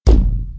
hit_spiderweb.ogg